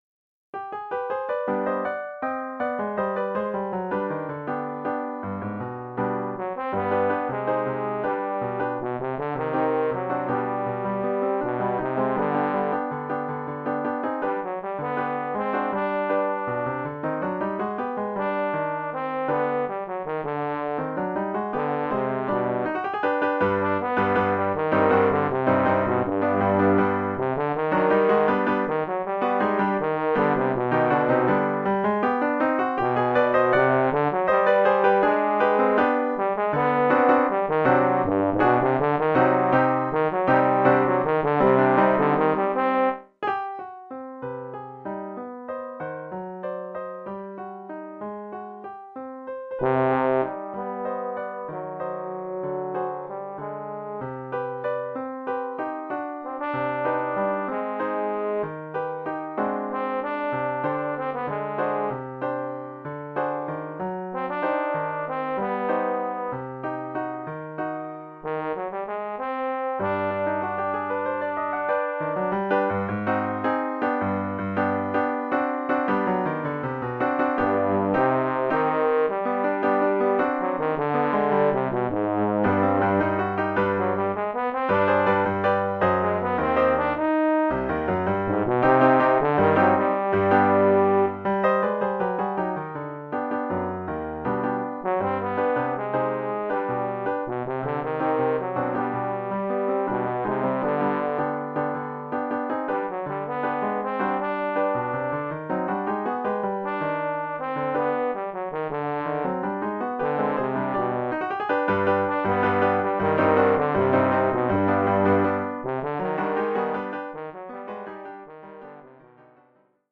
Oeuvre pour saxhorn basse / euphonium /
tuba et piano.